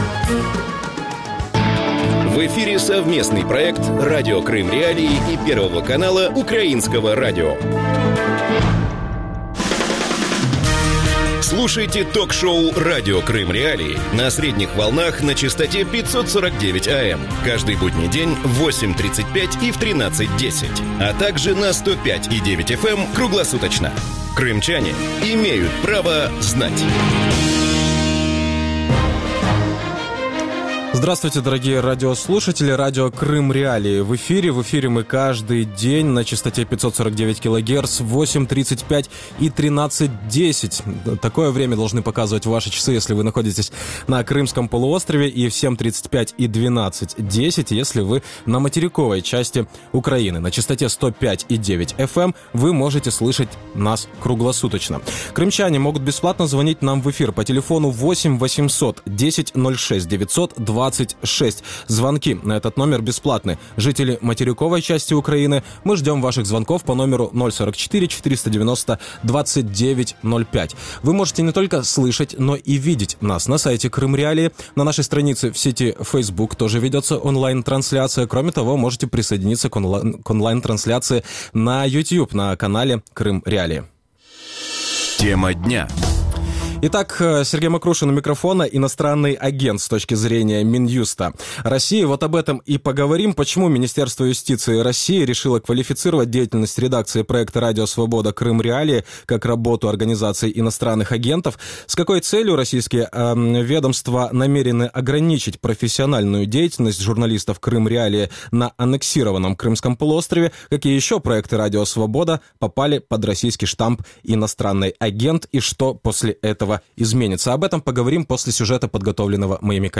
Какие еще проекты Радио Свобода попали под российский штамп «иностранный агент»? Гости эфира – слушатели.